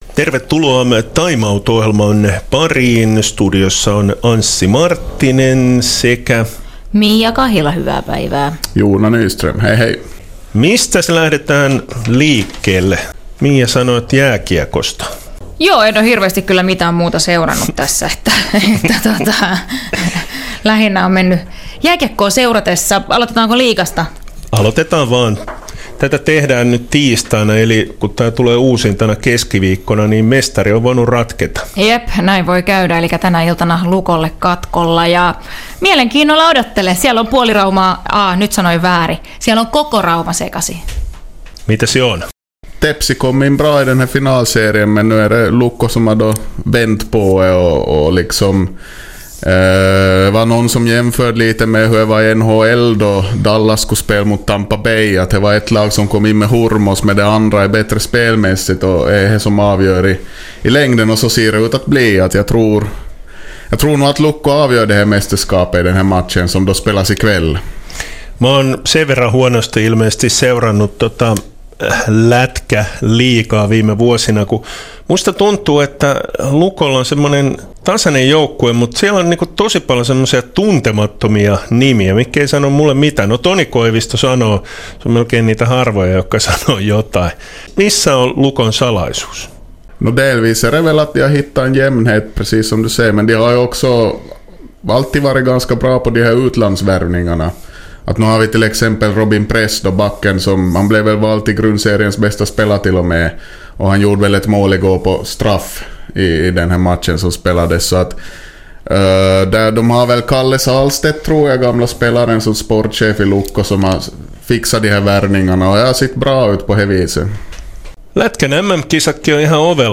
Kuumimmat puheenaiheet urheilussa. Tvåspråkig program.